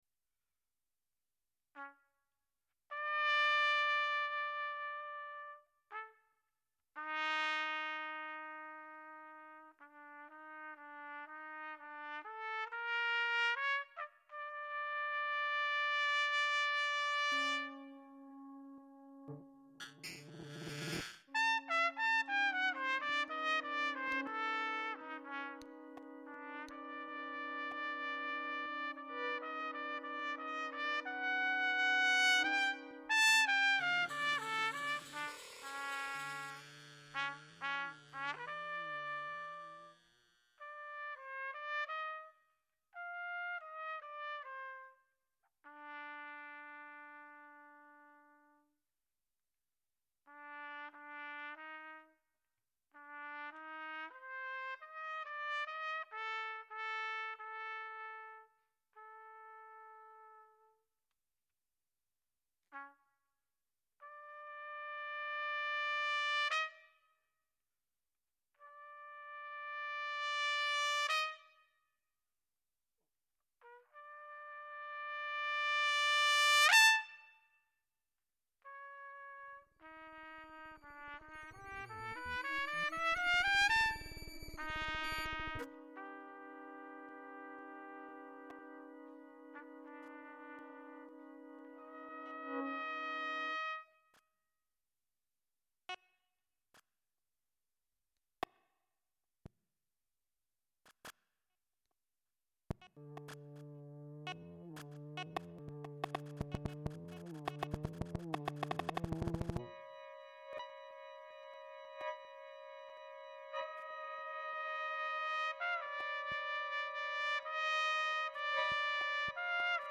trumpet Duration